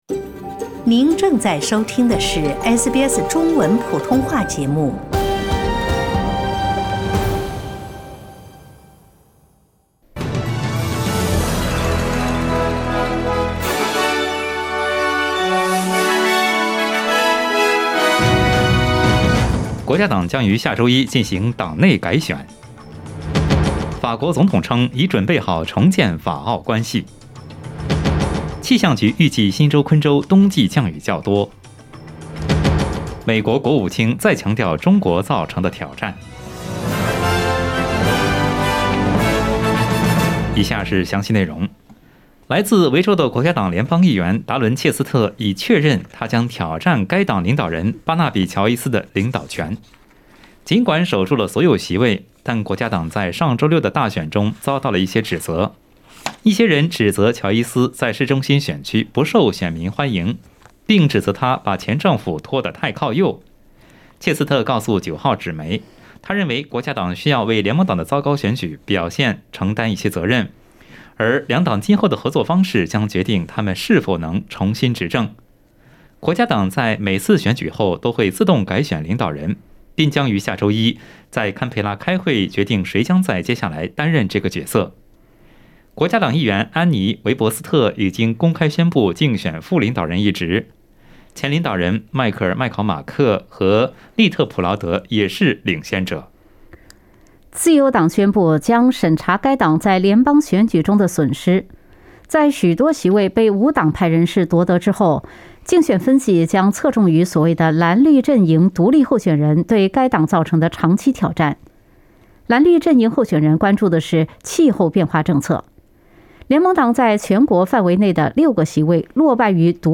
SBS早新闻（5月27日）
请点击收听SBS普通话为您带来的最新新闻内容。